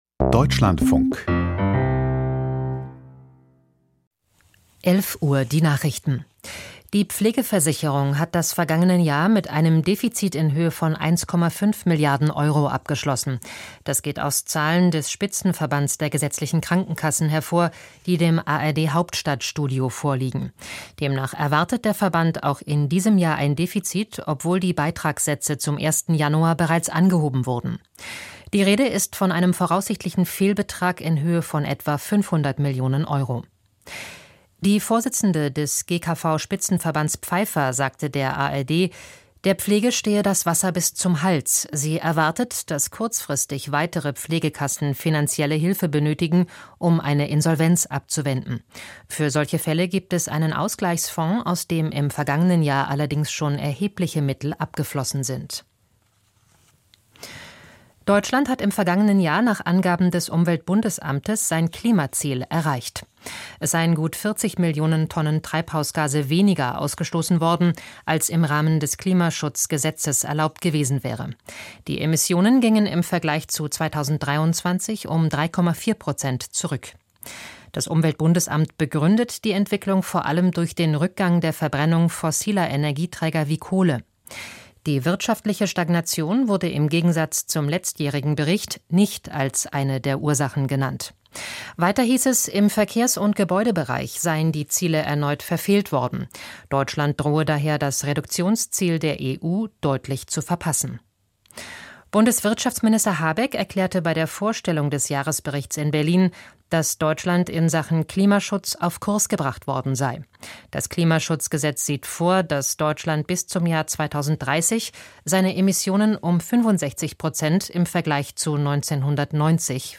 Die Deutschlandfunk-Nachrichten vom 14.03.2025, 11:00 Uhr